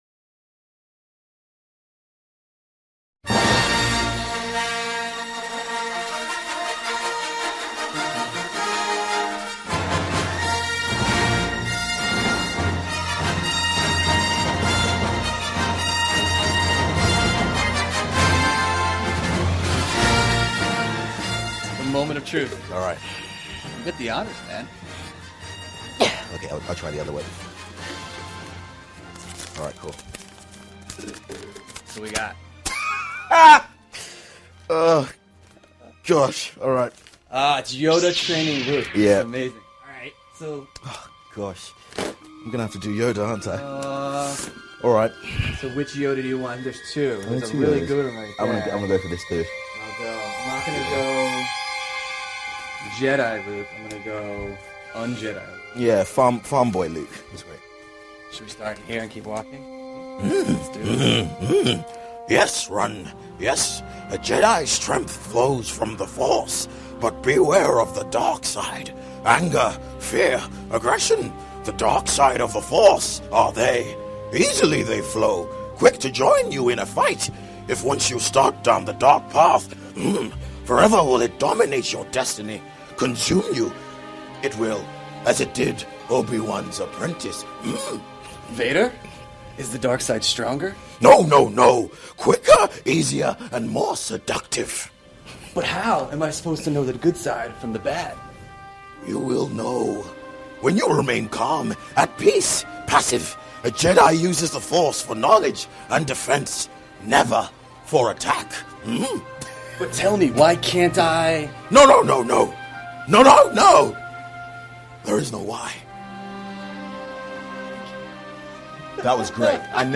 访谈录 《星球大战7》男主约翰波耶加讲述星战情怀 听力文件下载—在线英语听力室